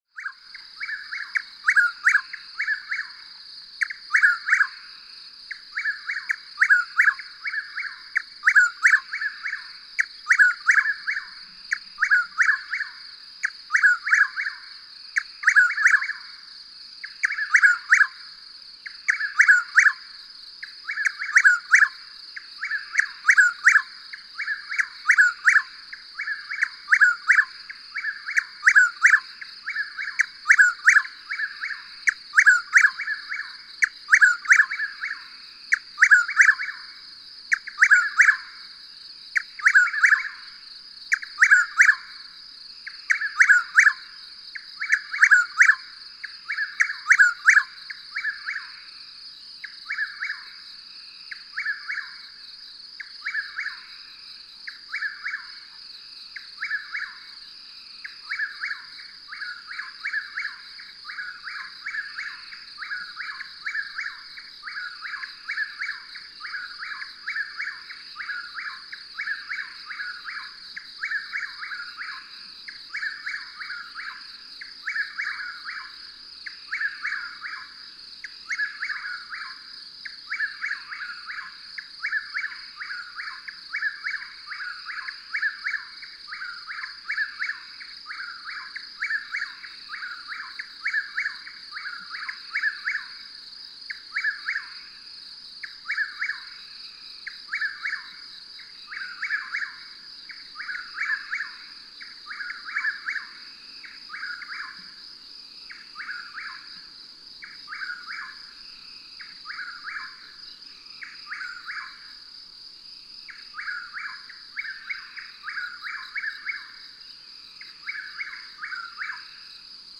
Chuck-will's-widow
Subchapter: Night singing
Hear the distinctive chuck-WILL's-WID-ow songs of these two unseen males near their territorial boundary—one song is a little more hurried than the other.
Bay Creek Wilderness, Shawnee National Forest, Illinois.
509_Chuck-will's-widow.mp3